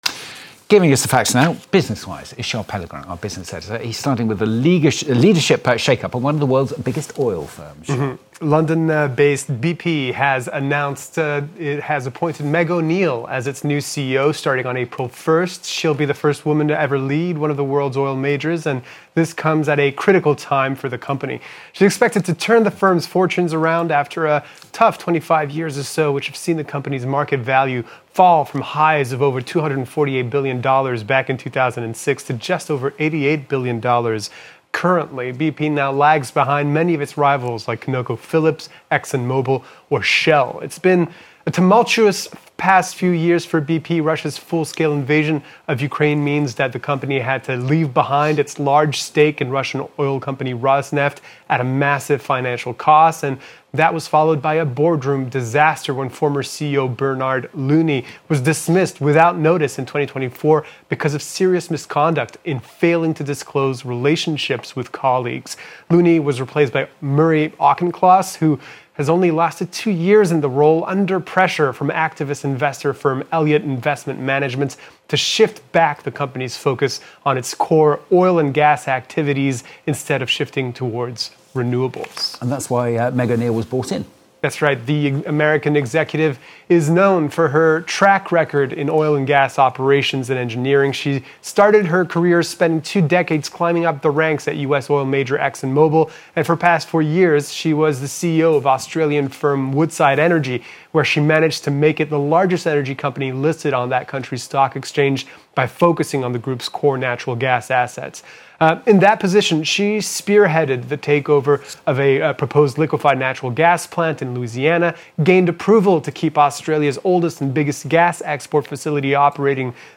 A daily update on the latest business and economic news. Live from Monday to Thursday at 9:15am and 6:45pm Paris time.